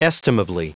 Prononciation du mot estimably en anglais (fichier audio)
Prononciation du mot : estimably